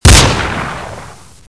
» выстрел Размер: 26 кб